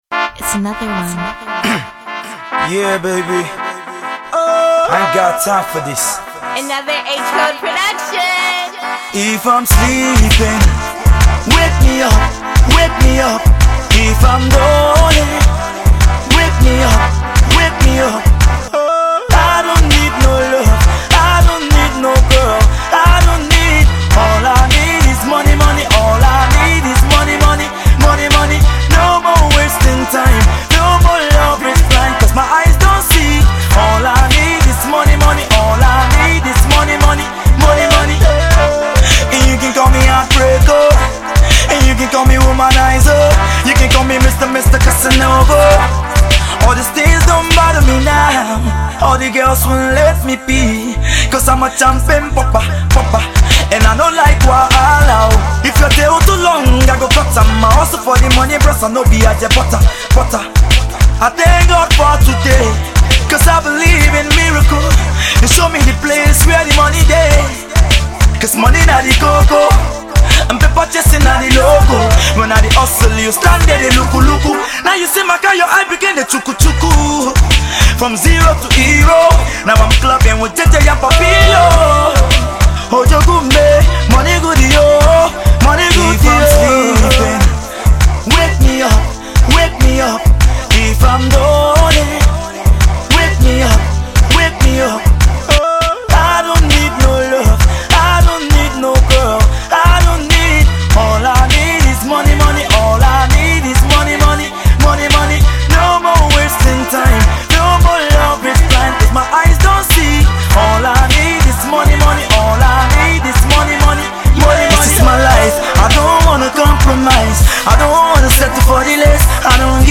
His vocal quality goes very well with his style